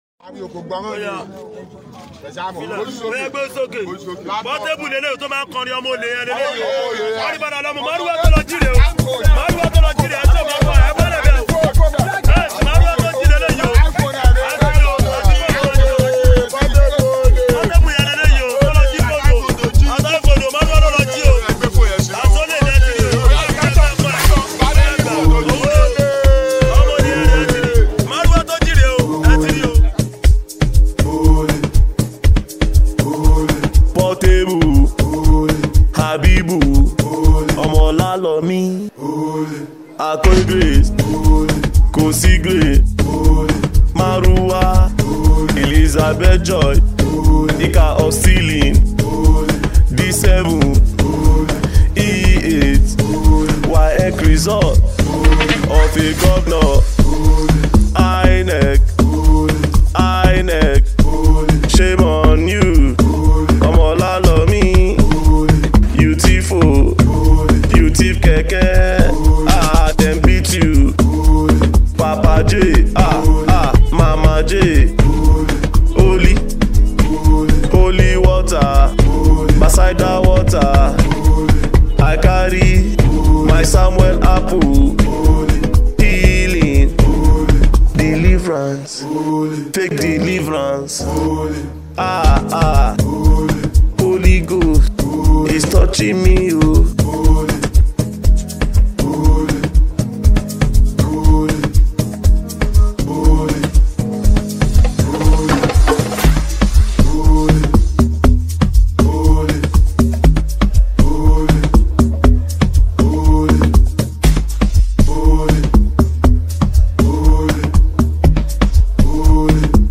delivers sharp lyrics and unapologetic energy in this track
wrapped in a rhythm that’s hard to ignore.